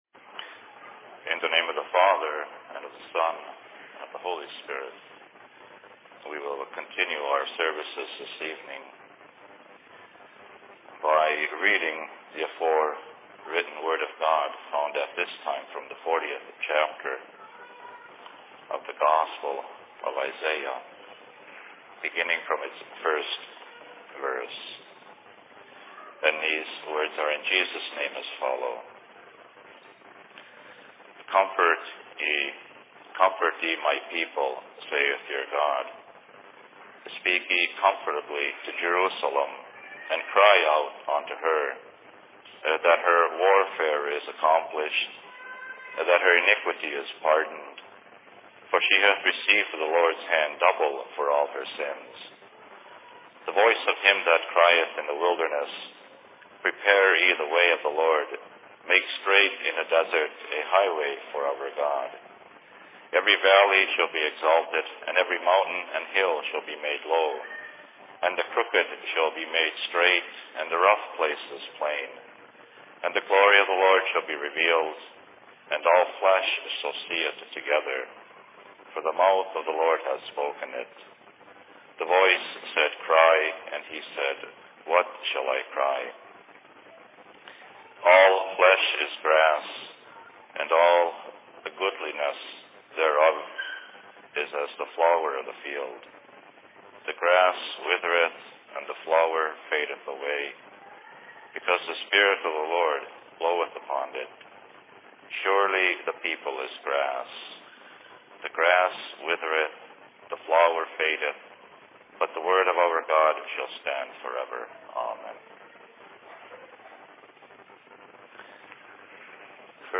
Thanks Giving services/Sermon in Minneapolis 25.11.2005
Location: LLC Minneapolis